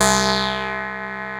DOBLEAD C3-L.wav